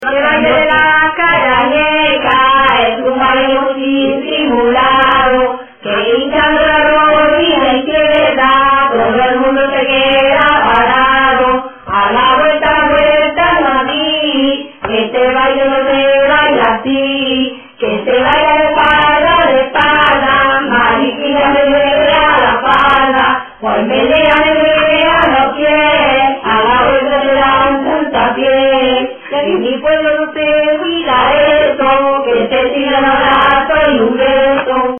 Materia / geográfico / evento: Canciones de corro Icono con lupa
Arenas del Rey (Granada) Icono con lupa
Secciones - Biblioteca de Voces - Cultura oral